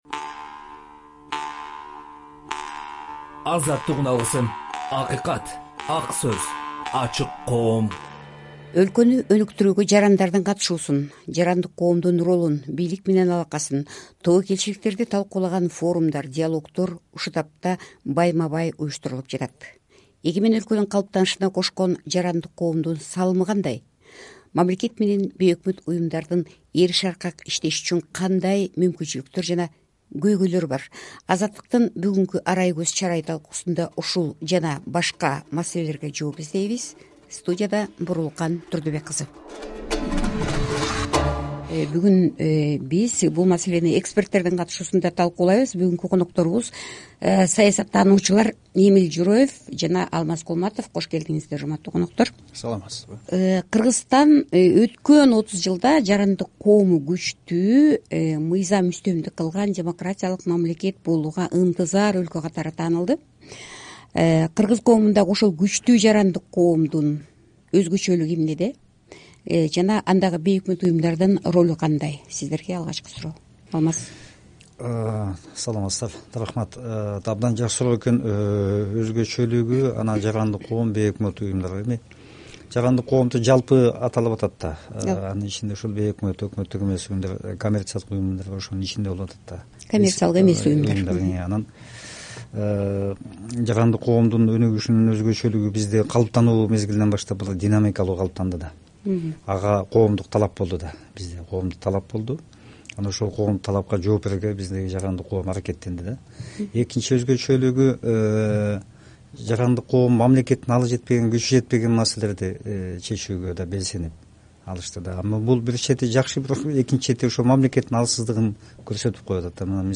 “Арай көз чарай” программасында ушул темада болгон талкууну "Ак сөз" подкастында сунуш кылабыз.